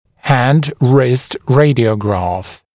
[hænd-rɪst ‘reɪdɪəugrɑːf] [-græf][хэнд-рист ‘рэйдиоугра:ф] [-грэф]рентгеновский снимок запястья